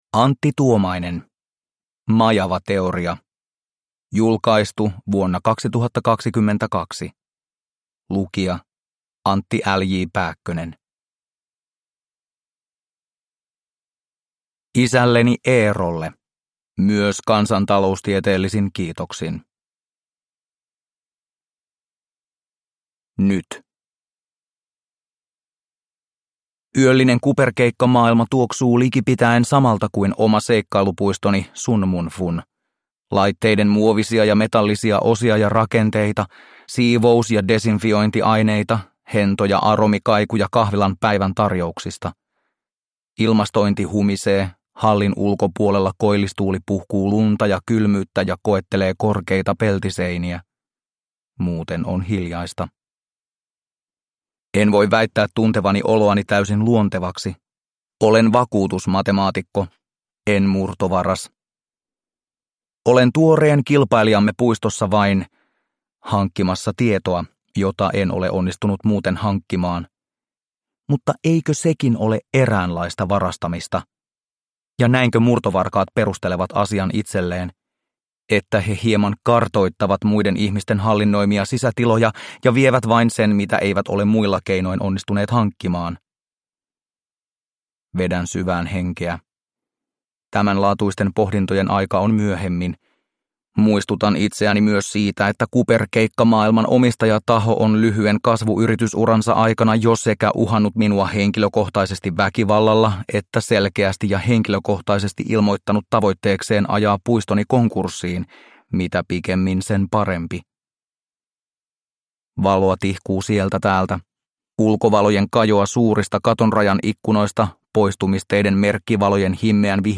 Majavateoria – Ljudbok
Uppläsare: